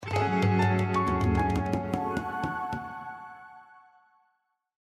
fail.mp3